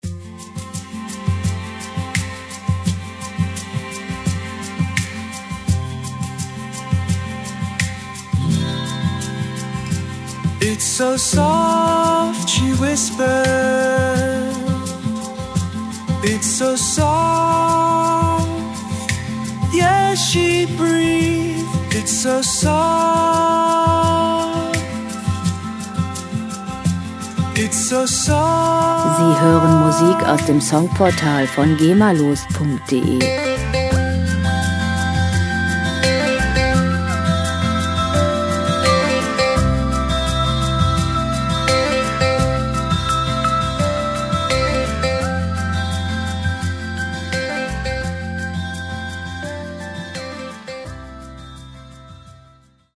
• Indie Pop Ballad